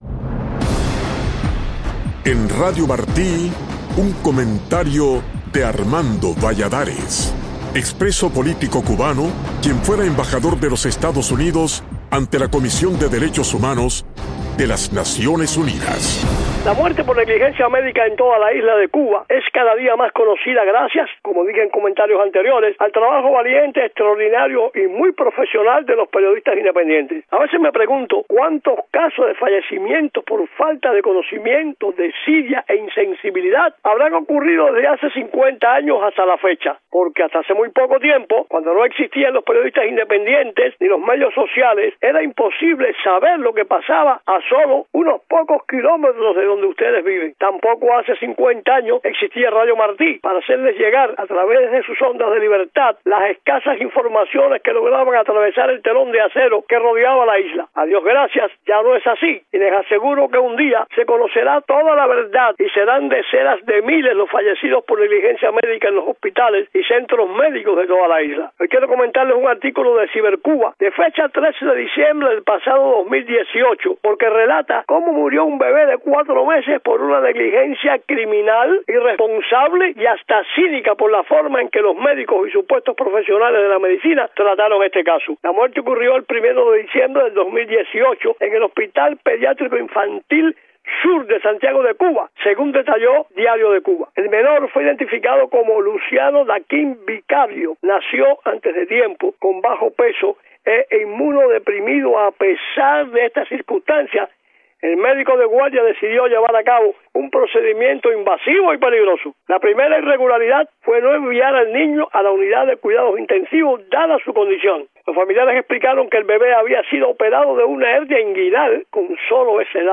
Embajador Valladares: Mi opinión